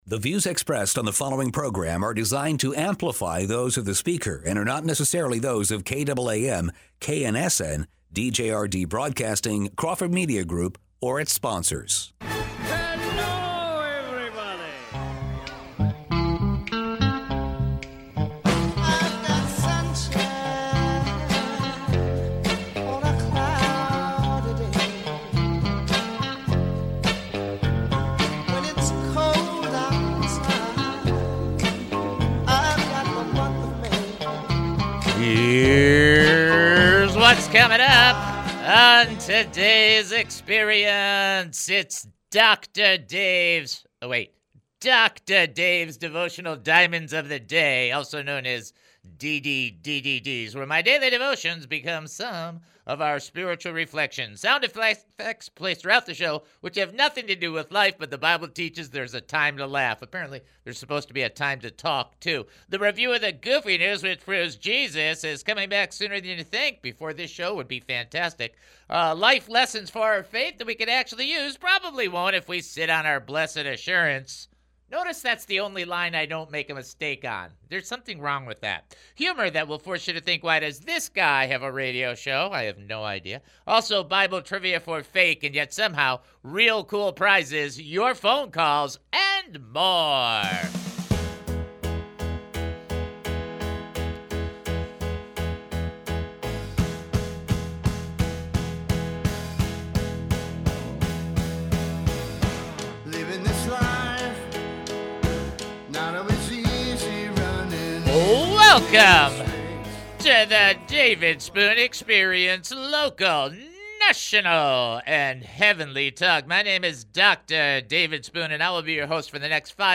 calls in with yet another brilliant question. He asks about the tension between God commanding Moses to challenge Pharaoh, but at the same time also knowing that God is going to harden Pharaoh's heart.